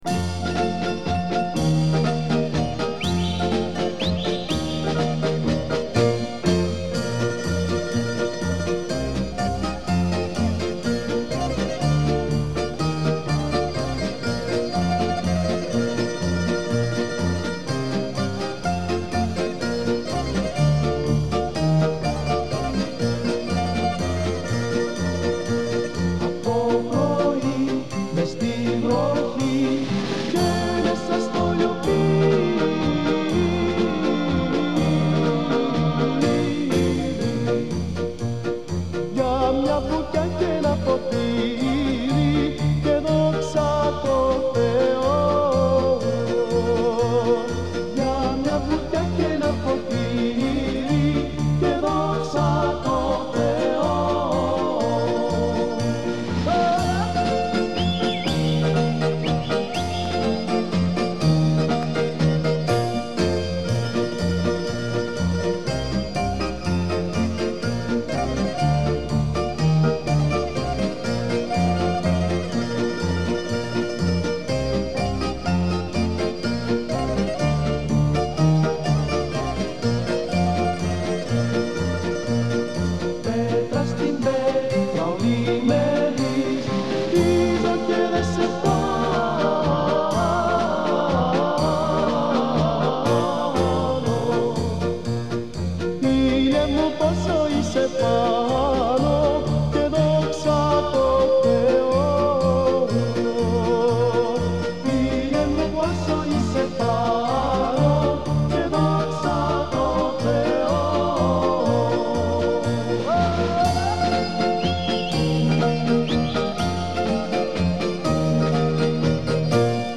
Музыкальное трио из солнечной Греции.
Музыкальный стиль Laika.
Genre: Folk, Instrumental